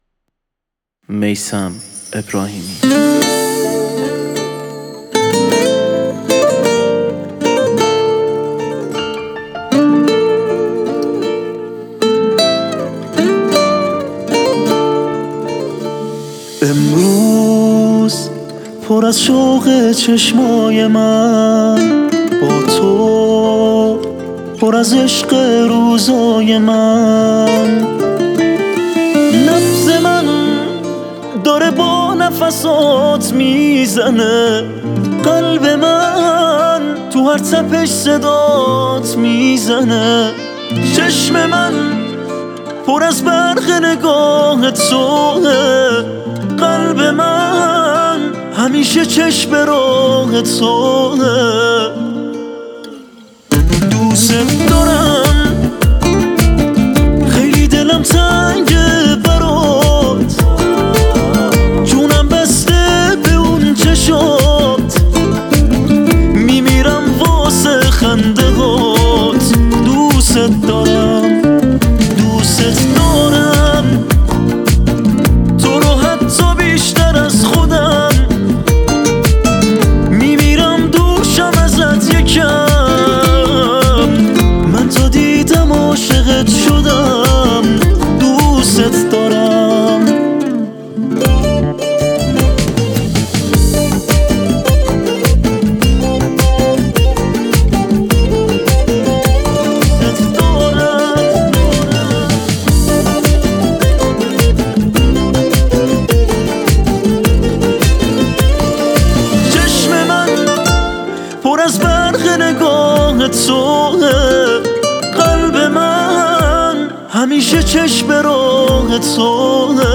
گیتار
بوزوکی
پاپ